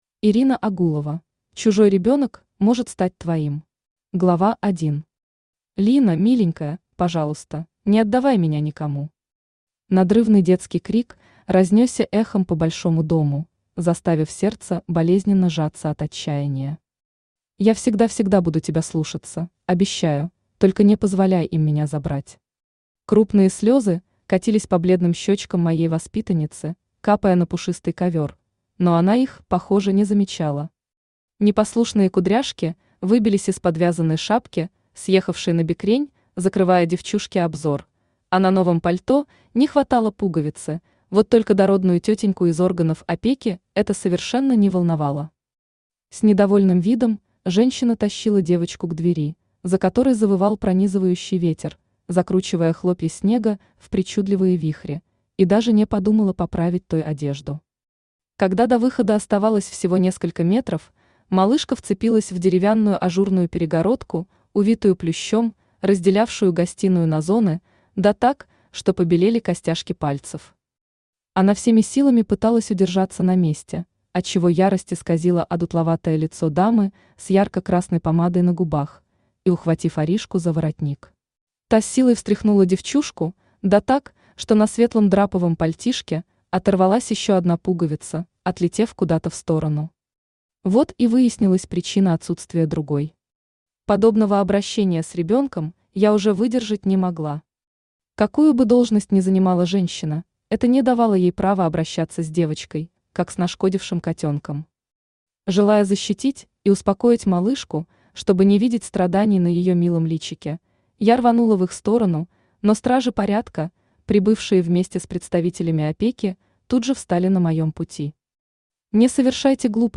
Аудиокнига Чужой ребёнок может стать твоим | Библиотека аудиокниг
Aудиокнига Чужой ребёнок может стать твоим Автор Ирина Агулова Читает аудиокнигу Авточтец ЛитРес.